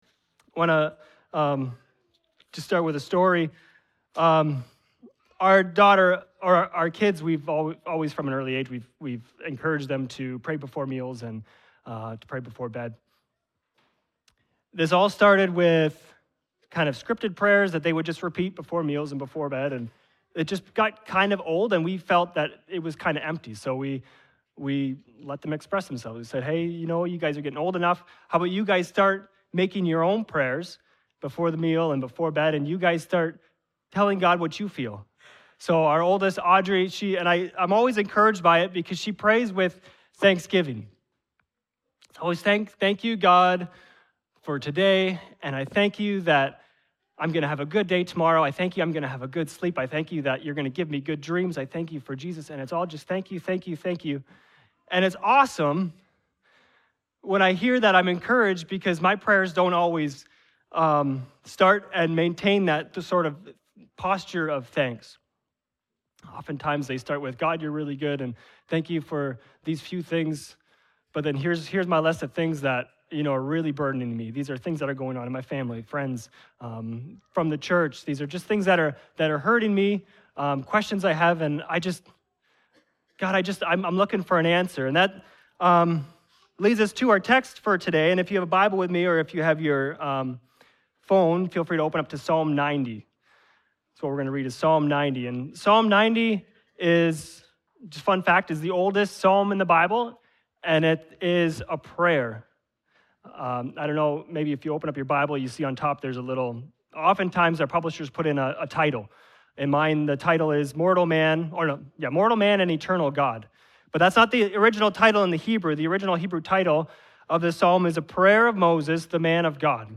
From Series: "Guest Preachers"
Psalm 90 reminds us of life’s brevity and the call to live with wisdom. This sermon, Making Life Count, reflects on how to number our days and find meaning before God.